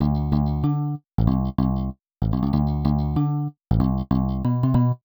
638Bass.wav